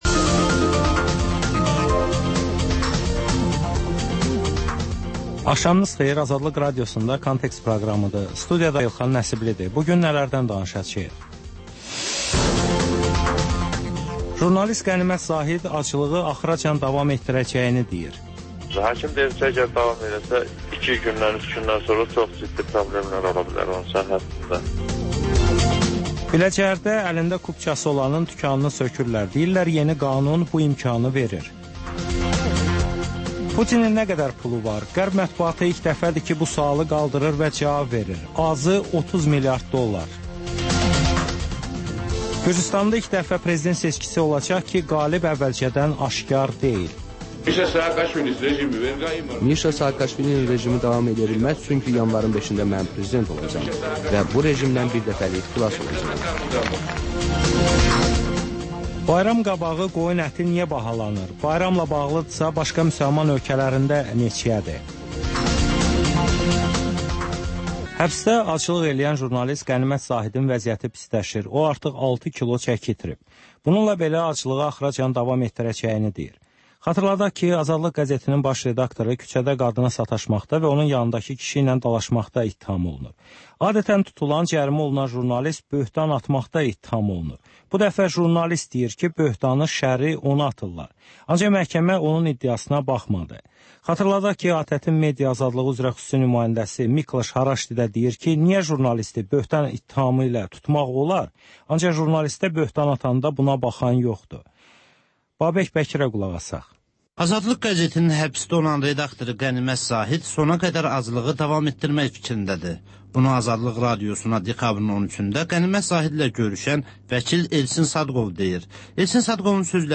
Hadisələrin təhlili, müsahibələr və xüsusi verilişlər.